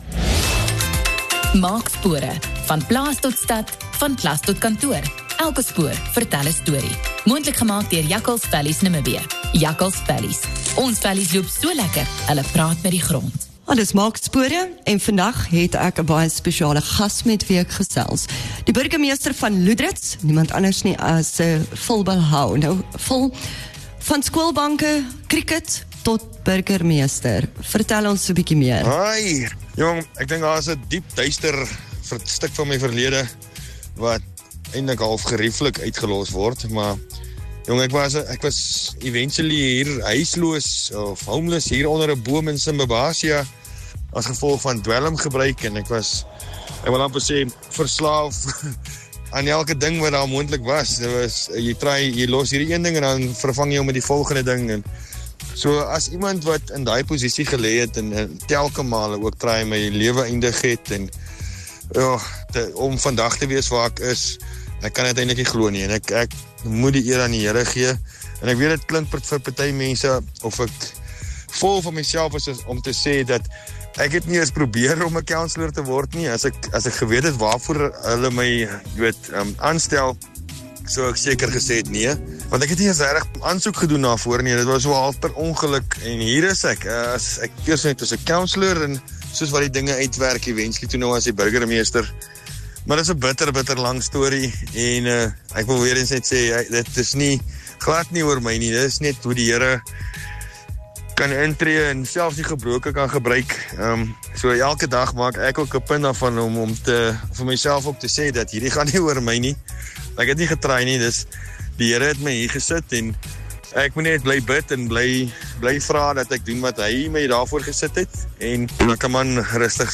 gesels met die burgemeester van Lüderitz, Phil Balhao.